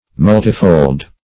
Multifold \Mul"ti*fold\ (m[u^]l"t[i^]*f[=o]ld), a. [Multi- +